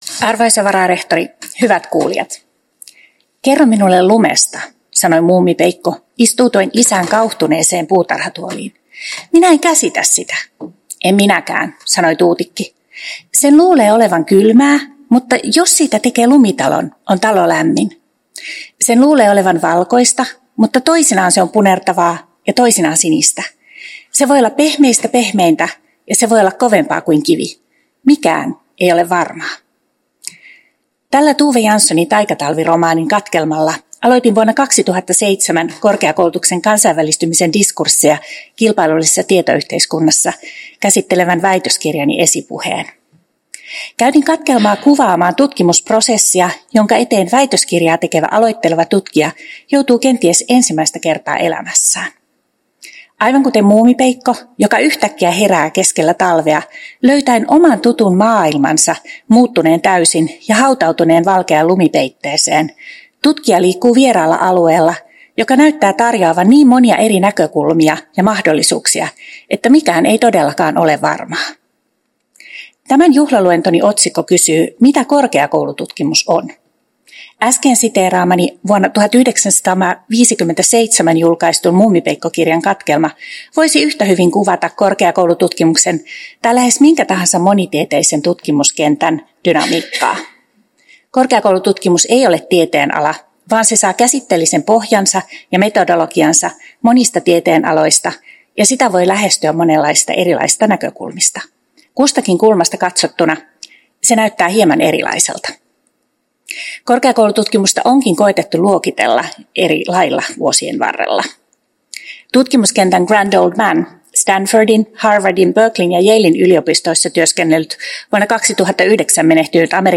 Uusien professoreiden juhlaluennot 10.12.2024